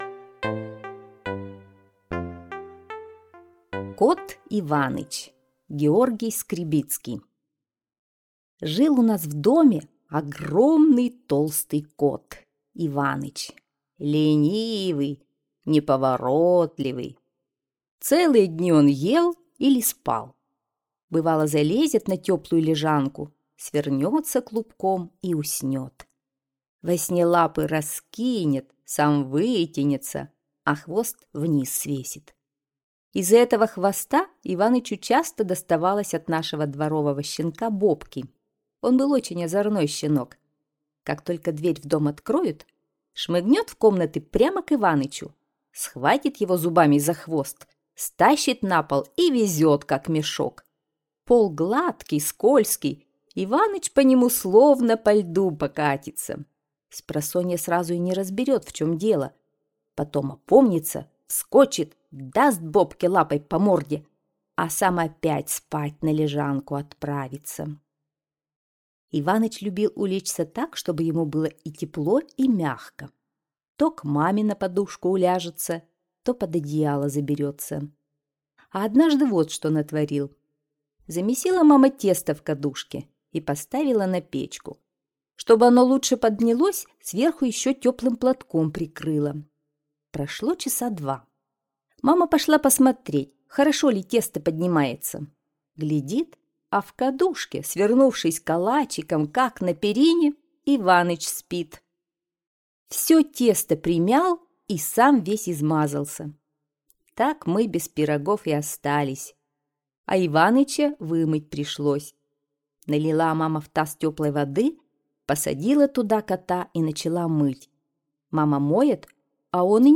Кот Иваныч - аудио рассказ Скребицкого Г.А. Рассказ про огромного толстого кота Иваныча, который любит поесть и поспать.